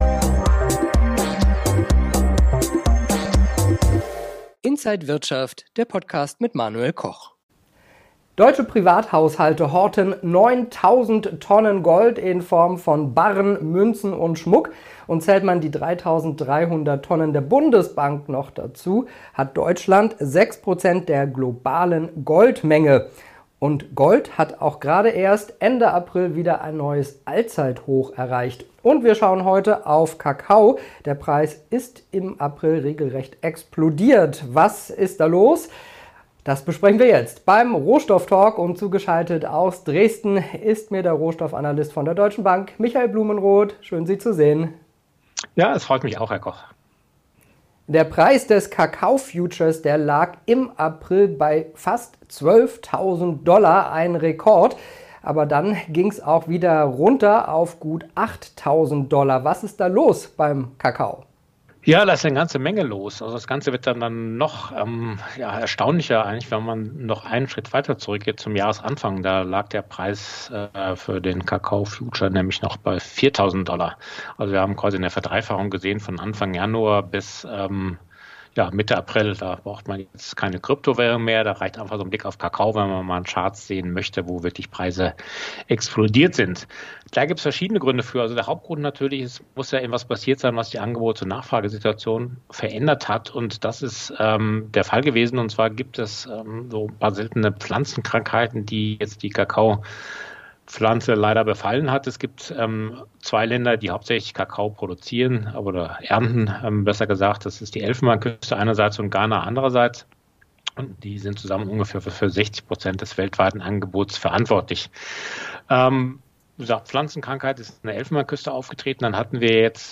im Rohstoff-Talk.